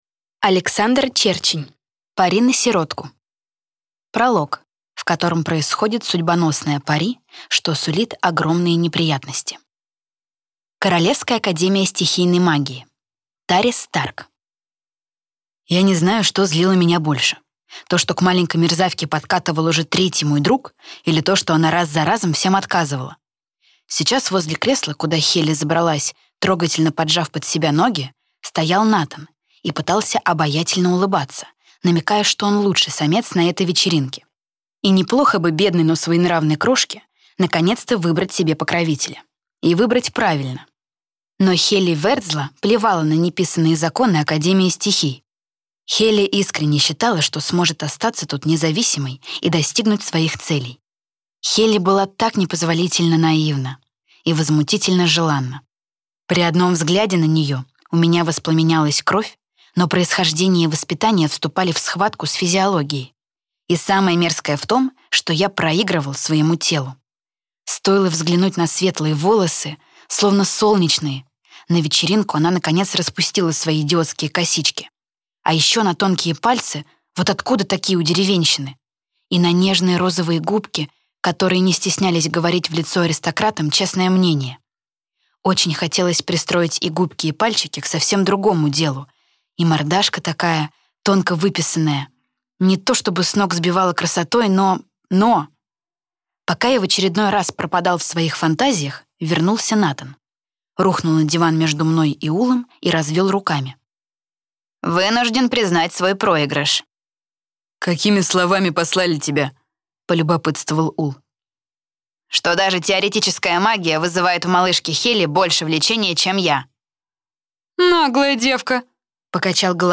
Аудиокнига Пари на сиротку | Библиотека аудиокниг